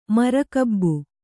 ♪ mara kabbu